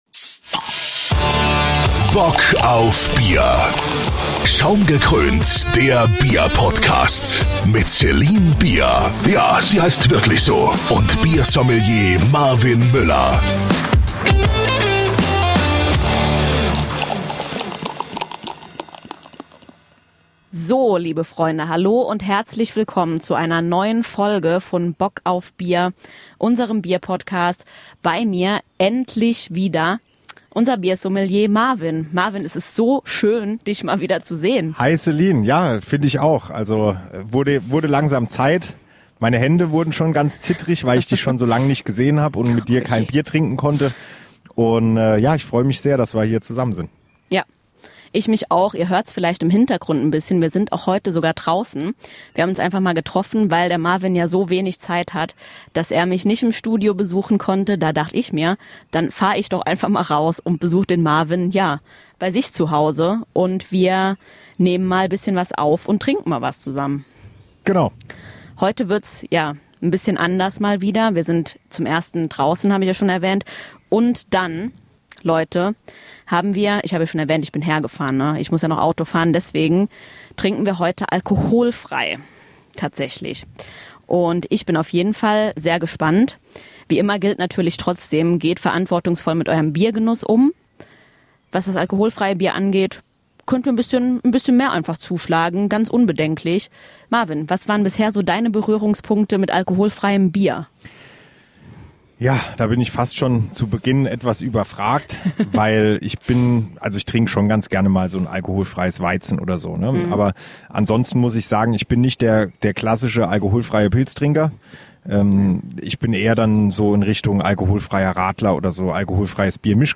Fragen über Fragen, die wir alle in dieser Folge für euch auflösen werden, denn das Team ist back together und diesmal nehmen wir sogar an einem ganze besonderen Ort auf.